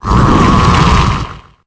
Cri de Wagomine dans Pokémon Épée et Bouclier.